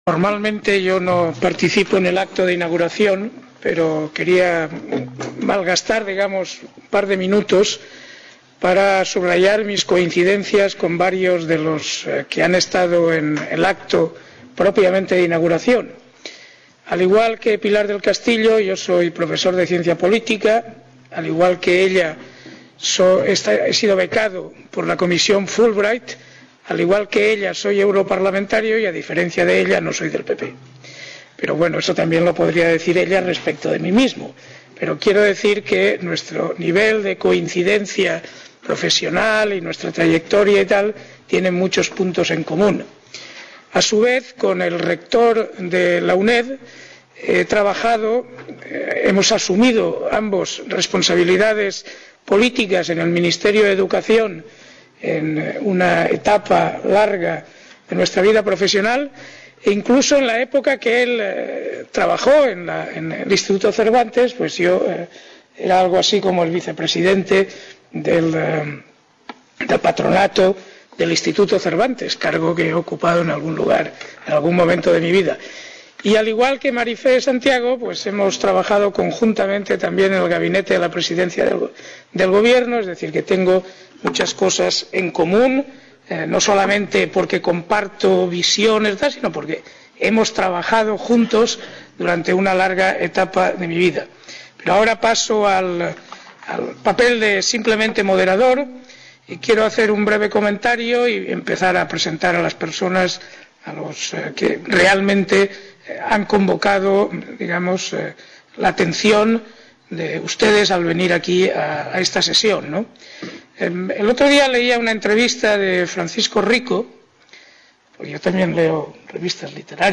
Inicio Mesa redonda: Literatura española y Europa
Enrique Guerrero Salom - Moderador